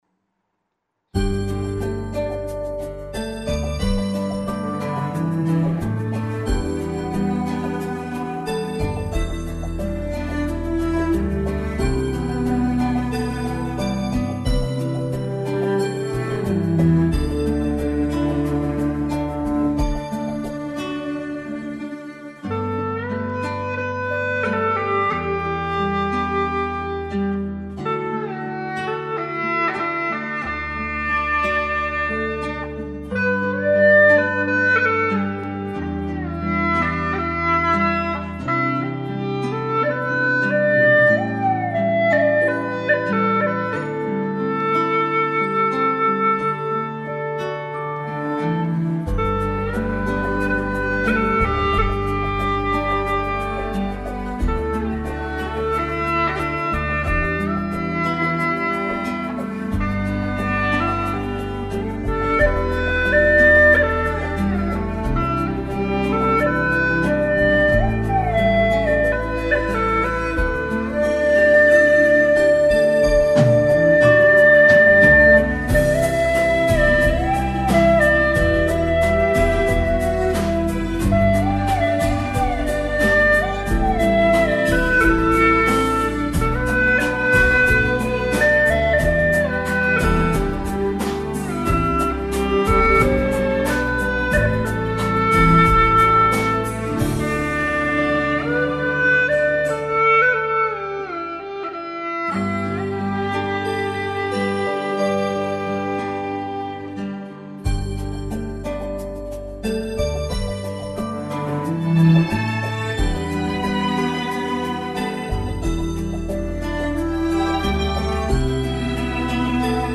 调式 : G 曲类 : 影视
【G调】